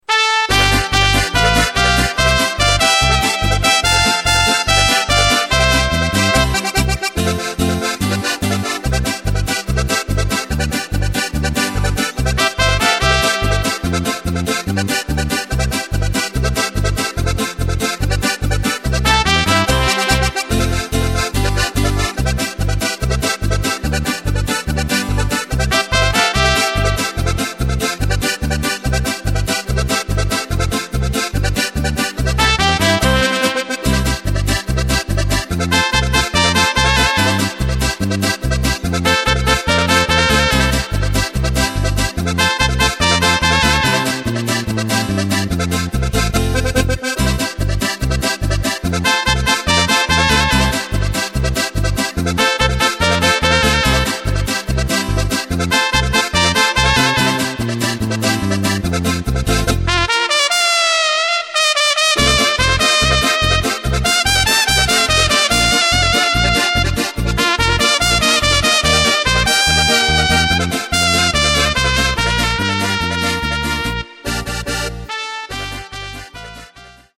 Takt: 2/4 Tempo: 144.00 Tonart: C
Flotte Polka aus dem Jahr 1991!
mp3 Playback Demo